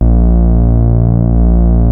P.5 A#2.4.wav